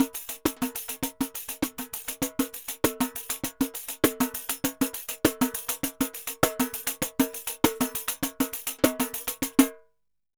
Pandeiro 2_Samba 100_4.wav